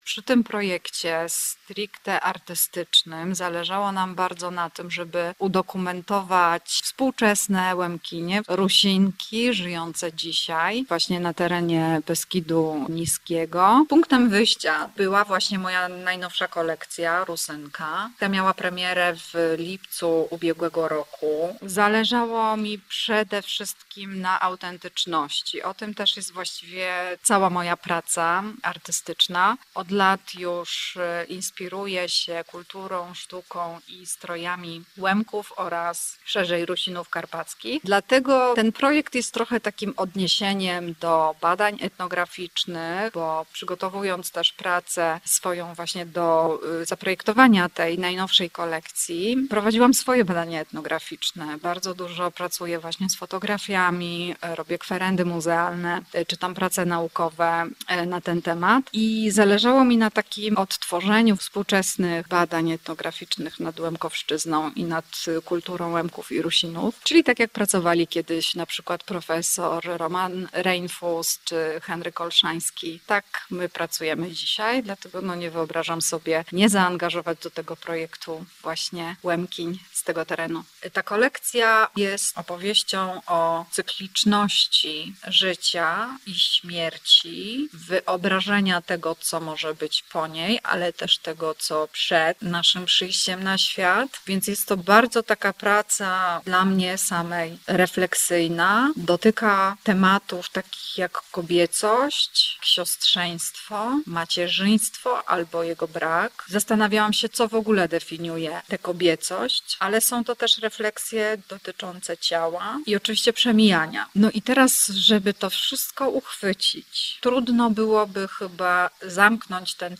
Twórcy projektu oraz jego bohaterki opowiadały o tym, w jaki sposób kultura regionu może stać się inspiracją dla współczesnej mody.